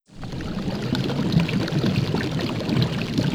lava.wav